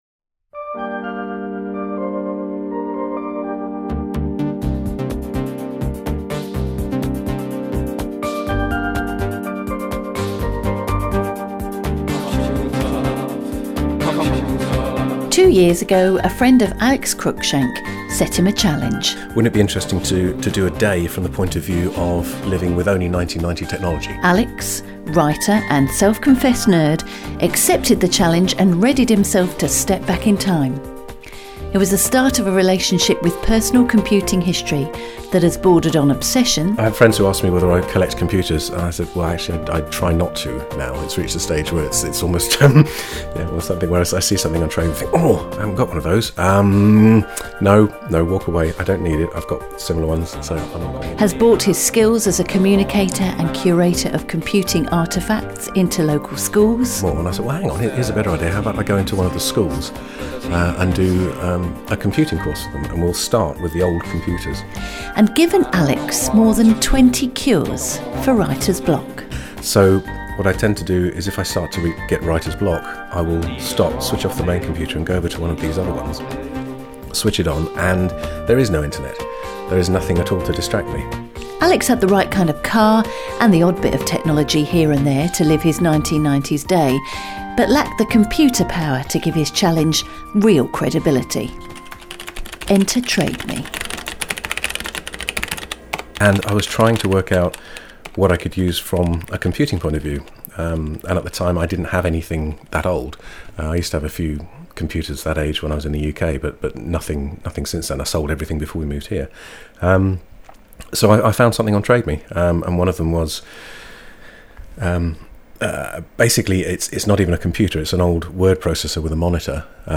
Various details about some of the other machines I saw the day we recorded the interview: